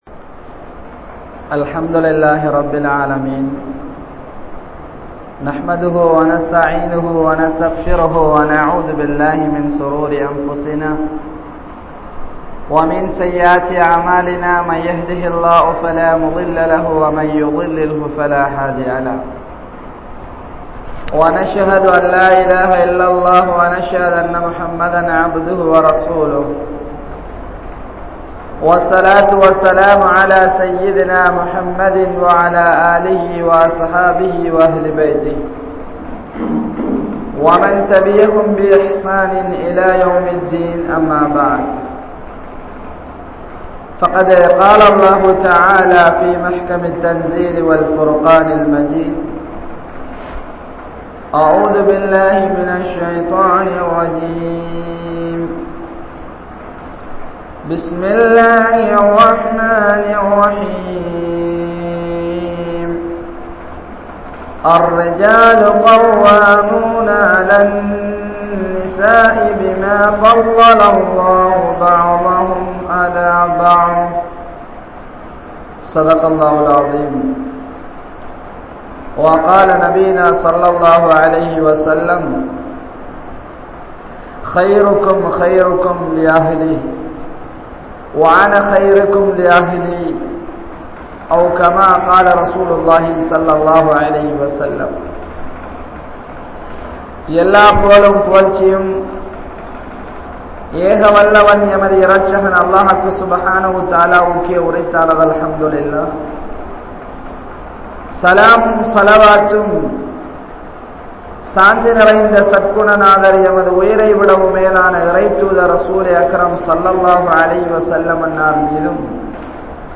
Kanavan & Manaivien Kadamaihal (கணவன் மனைவியின் கடமைகள்) | Audio Bayans | All Ceylon Muslim Youth Community | Addalaichenai